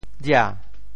偌 部首拼音 部首 亻 总笔划 10 部外笔划 8 普通话 ruò 潮州发音 潮州 ria2 文 中文解释 偌 <代> 如此;这样 [so;such]。